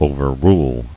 [o·ver·rule]